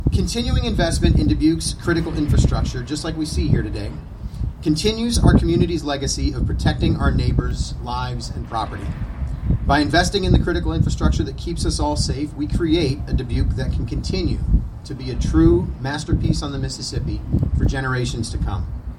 The City of Dubuque held a project kick-off for the Dubuque Gate and Pump Station Flood Mitigation Project Tuesday at the 16th Street Detention Basin Overlook.
During the event, Mayor Cavanagh emphasized the importance of investing in Dubuque infrastructure.